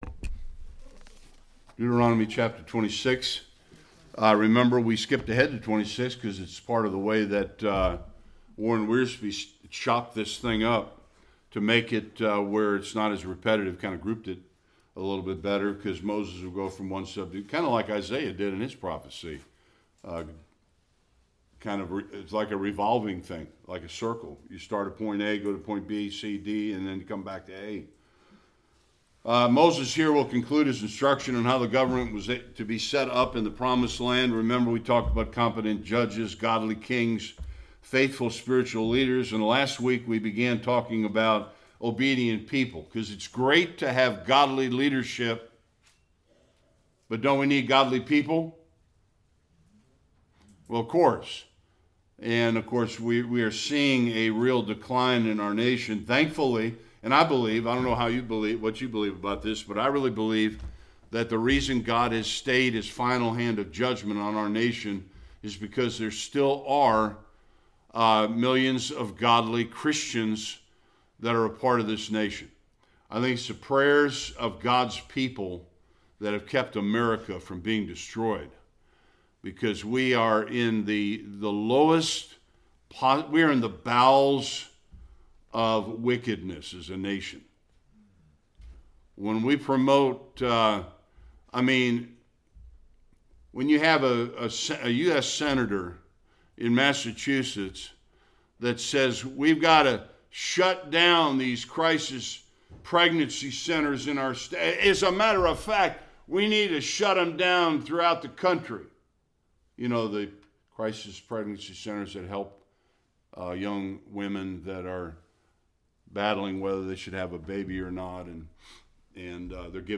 11-19 Service Type: Sunday School Moses describes some requirements of worship when Israel enters the Promised land.